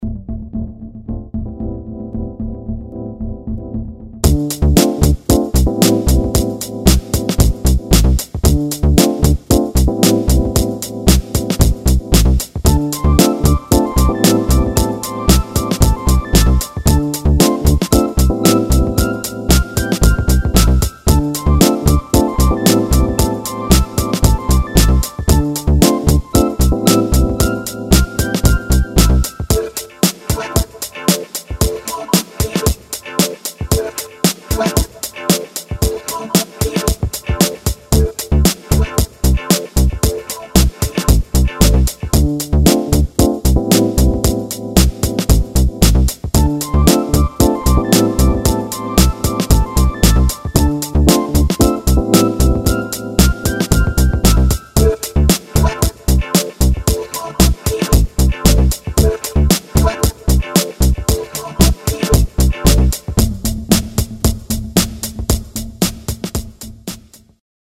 lounge - chill - detente - aerien - guitare